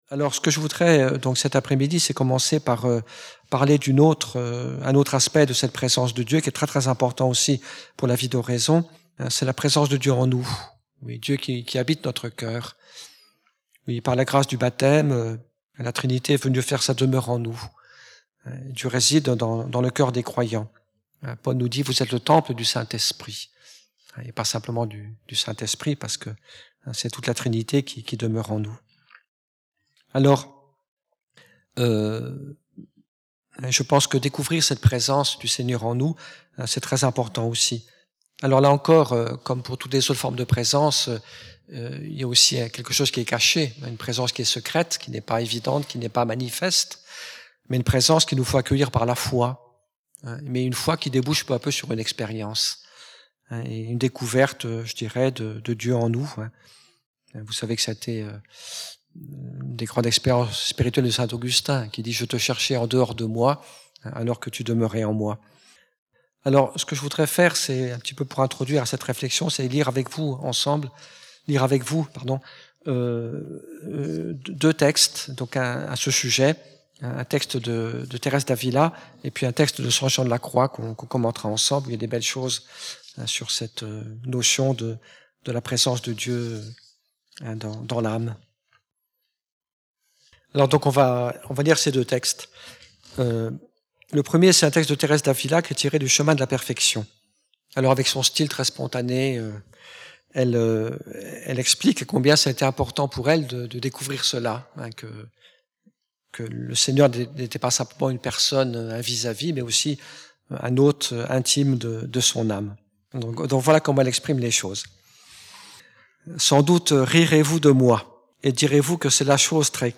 Un CD MP3* regroupant 6 enseignements: